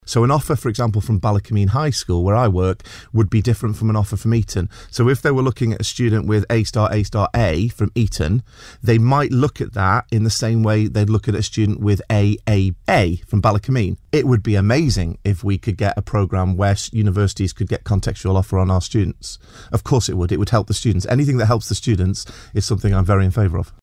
Speaking on the Mannin Line